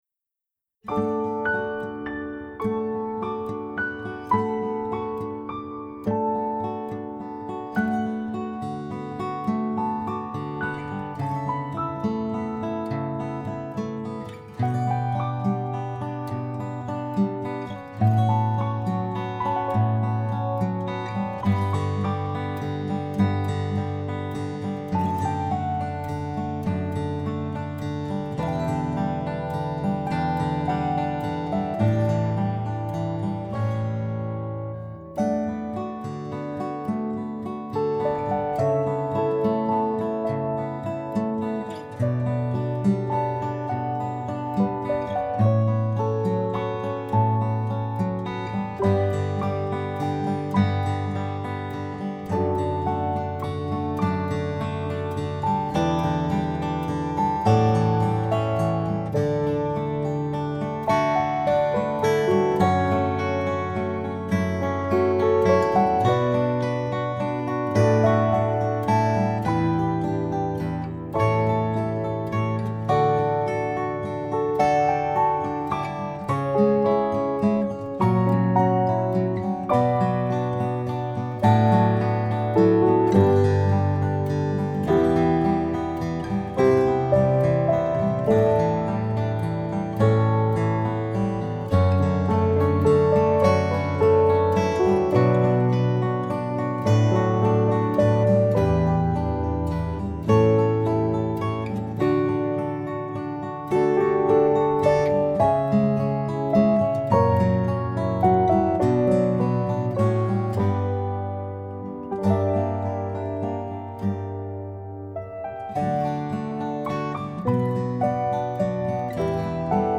Someone To Love You Piano Guitar 2020
someone-to-love-you-piano-guitar-7-4-20-n.mp3